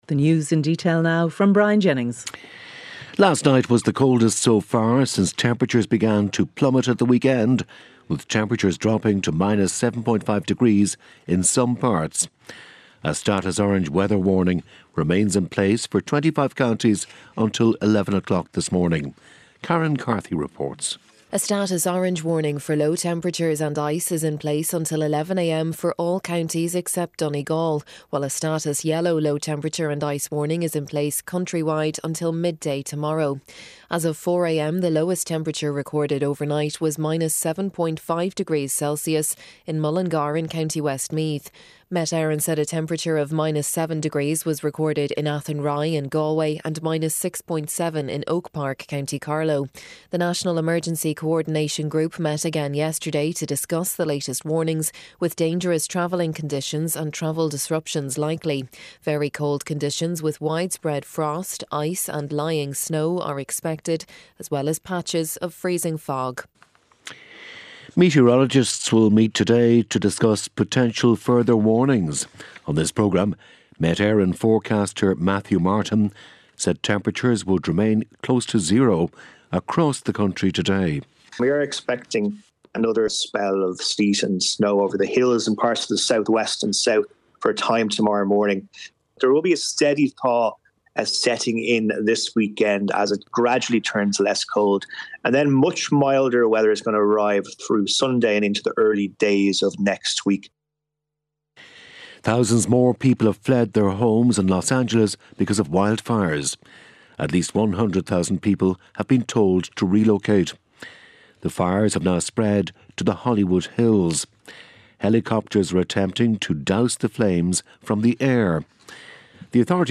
8am News Bulletin